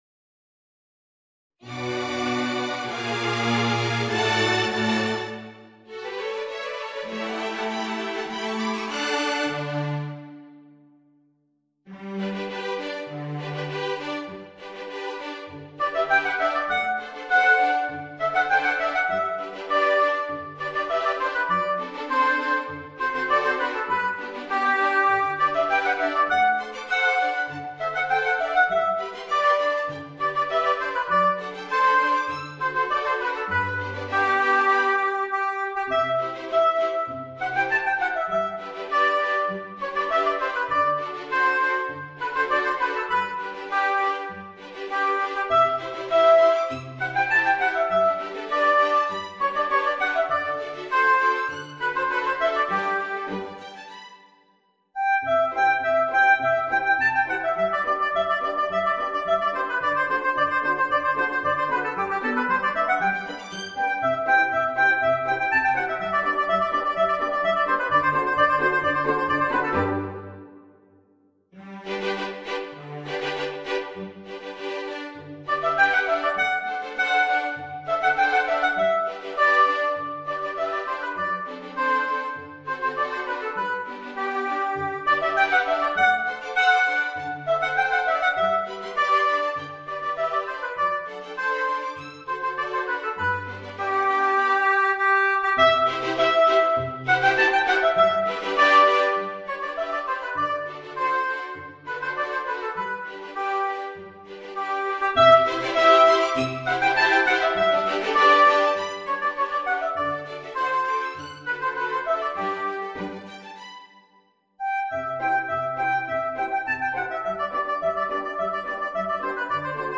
Voicing: Violin and String Orchestra